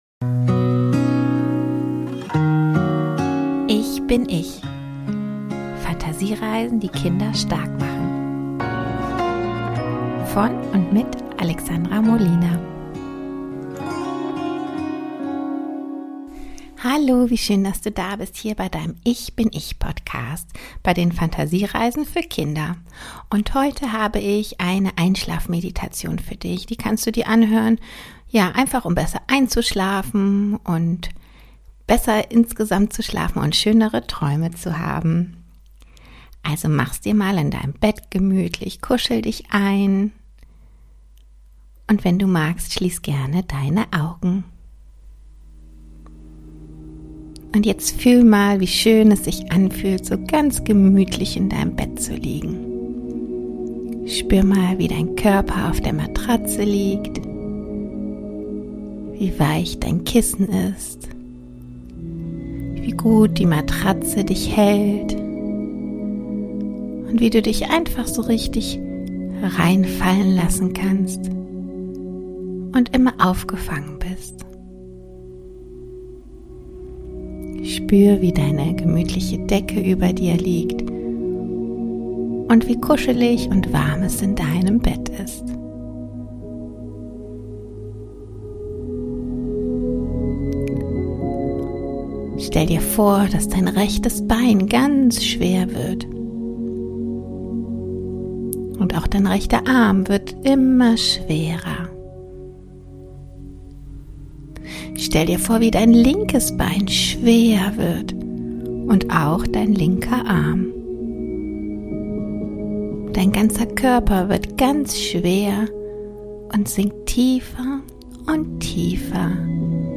Schlaf gut! Einschlafmeditation für Kinder